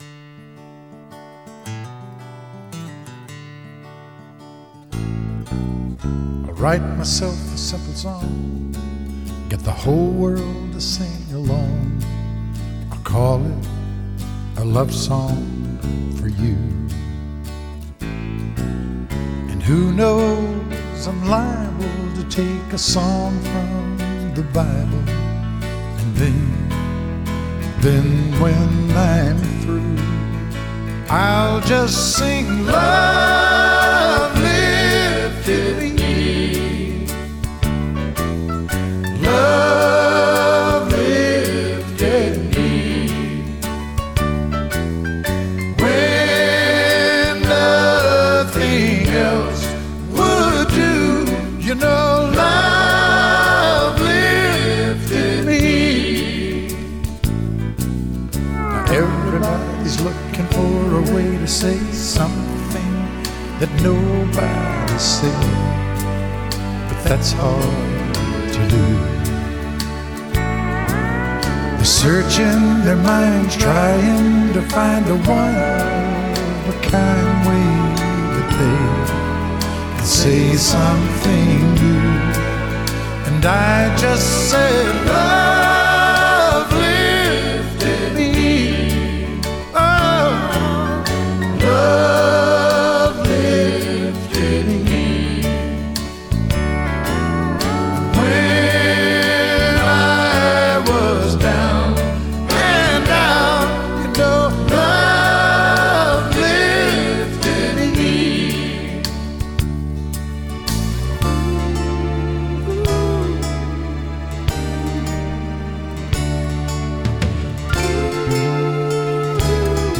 美国乡村音乐